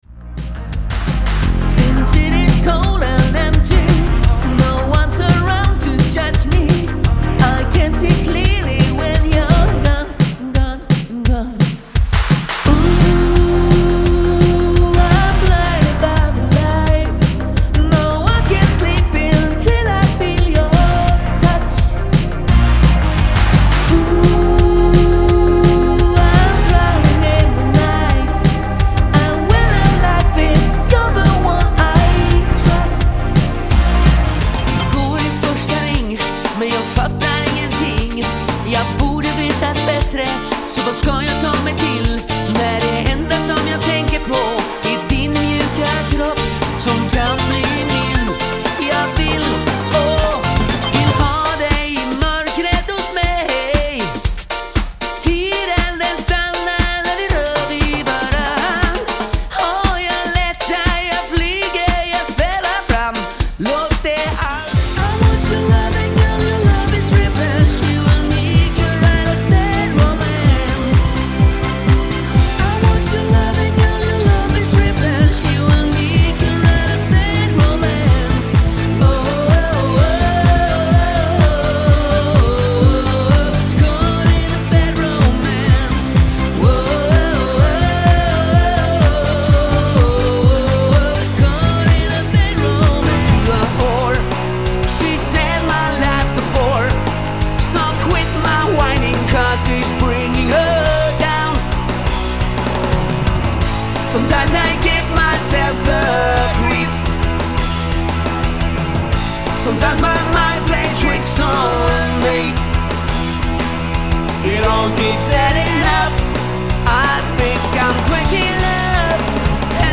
ett coverband